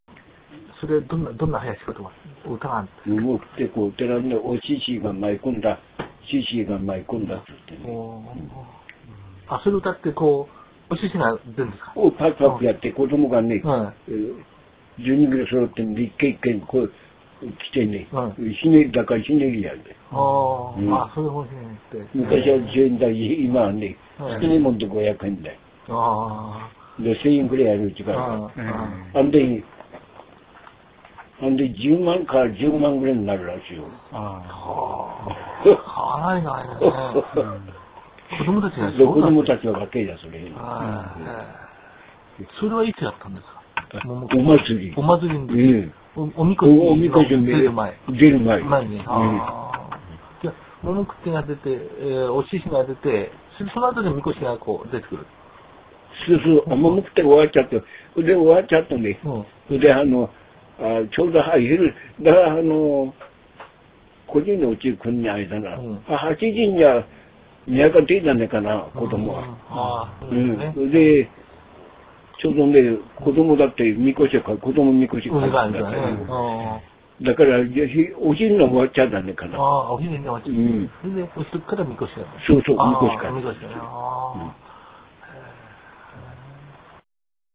毎年7月22日、八坂神社夏季例大祭での神輿渡御に先立ち、子供たちが獅子頭を先頭に椎津地区の各家々を「モモクッテ　コテェランネ　おししが舞い込んだ！！」と叫びながら廻る。
（はやしことば）